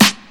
• Studio Steel Snare Drum Sample A Key 11.wav
Royality free snare tuned to the A note. Loudest frequency: 2842Hz
studio-steel-snare-drum-sample-a-key-11-eso.wav